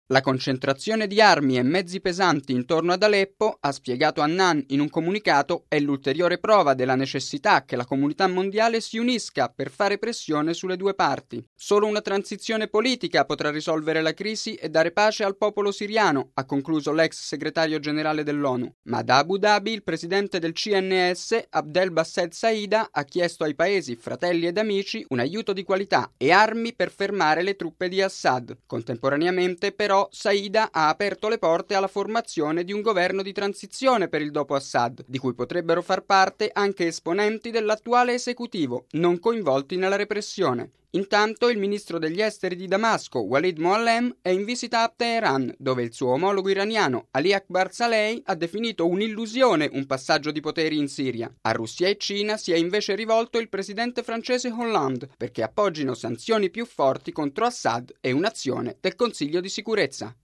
Radiogiornale del 29/07/2012 - Radio Vaticana